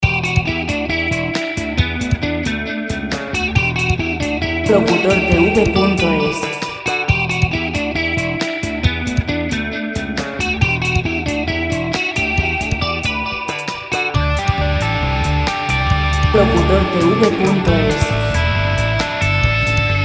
Música  pop libre de derechos de autor.